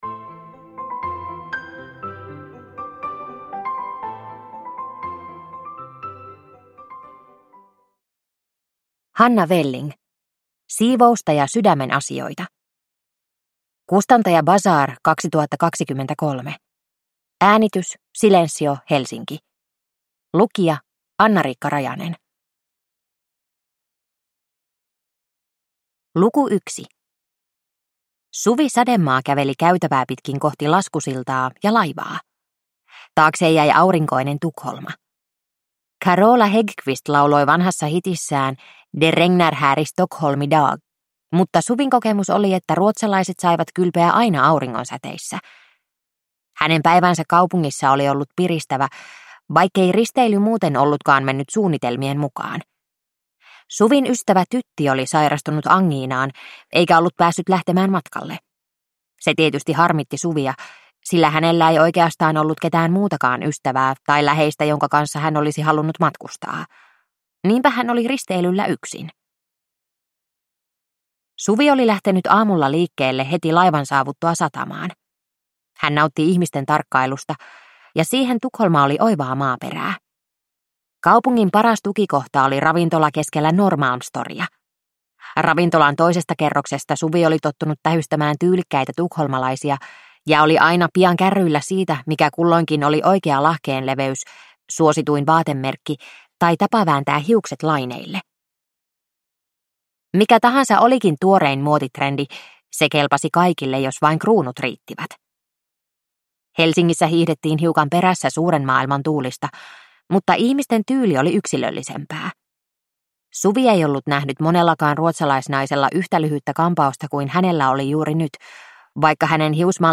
Siivousta ja sydämen asioita – Ljudbok – Laddas ner